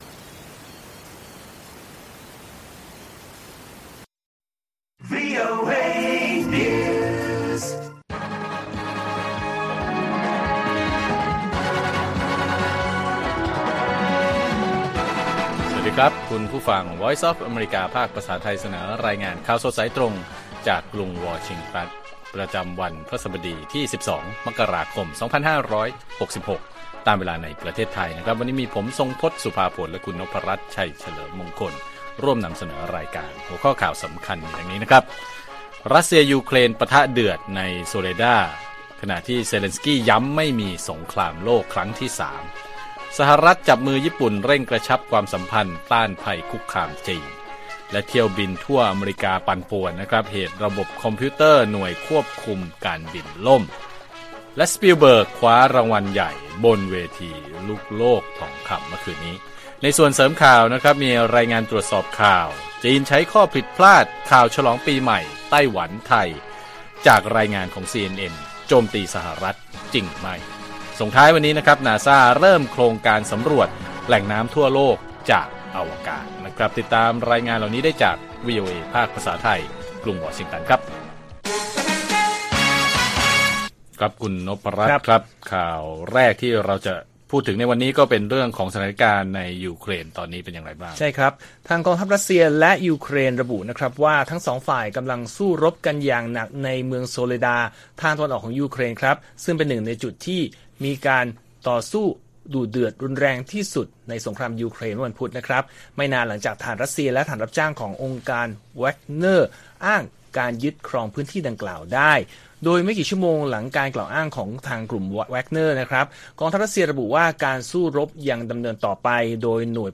ข่าวสดสายตรงจากวีโอเอไทย พฤหัสบดี ที่ 12 ม.ค. 66